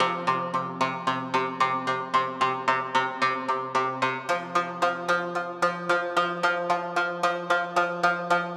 Index of /DESN275/loops/Loop Set - Spring - New Age Ambient Loops/Loops
Syrup_112_C_PluckedString.wav